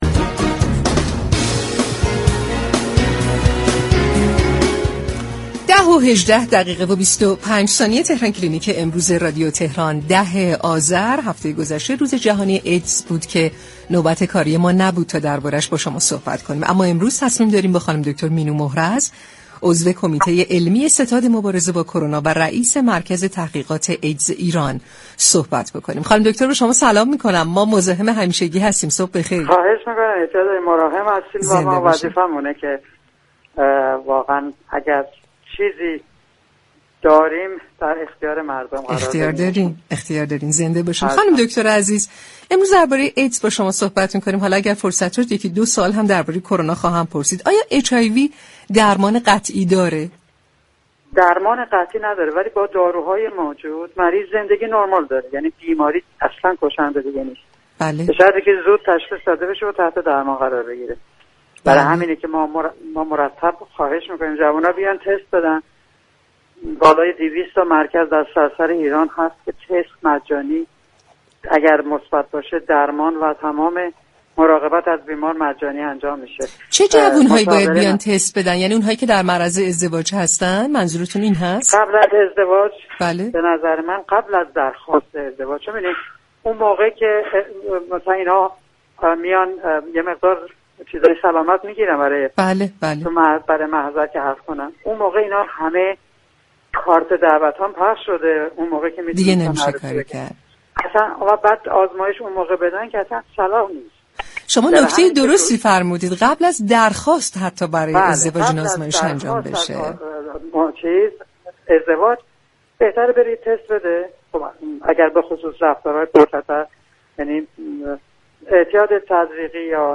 به گزارش پایگاه اطلاع رسانی رادیو تهران، دكتر مینو محرز در گفتگو با برنامه تهران كلینیك با تاكید بر اینكه بیماری ایدز، درمان قطعی ندارد گفت: بیمار با داروهای موجود، زندگی نرمال دارد.